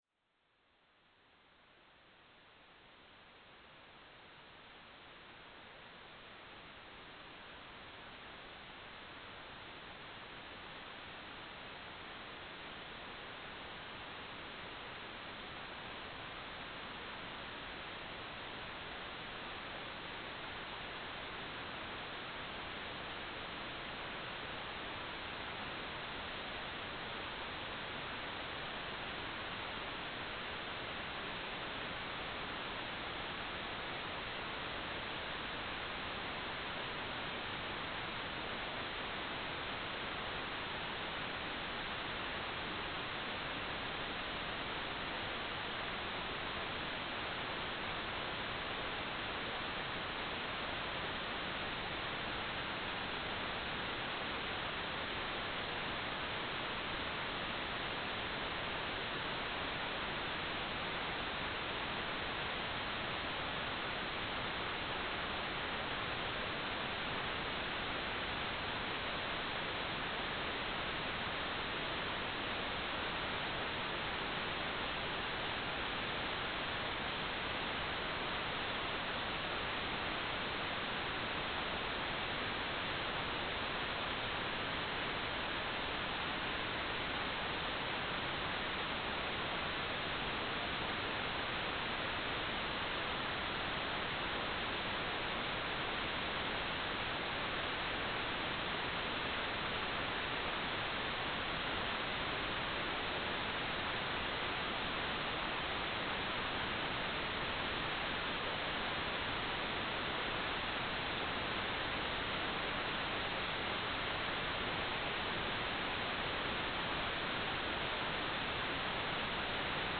"transmitter_description": "carrier wave",
"transmitter_mode": "CW",